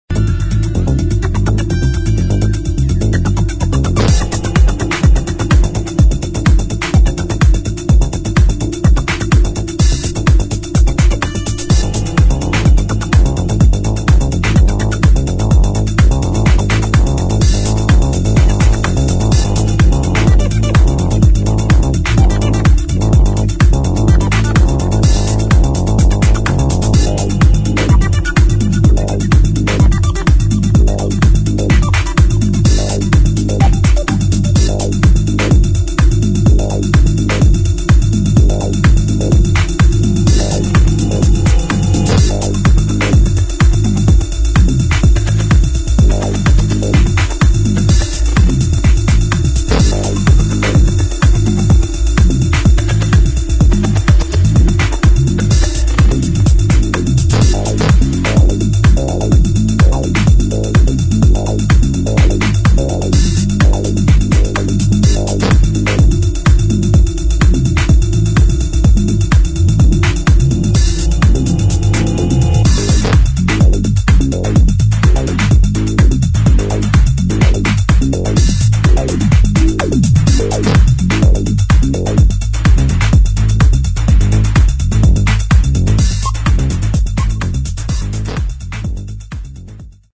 [ TECHNO / HOUSE ]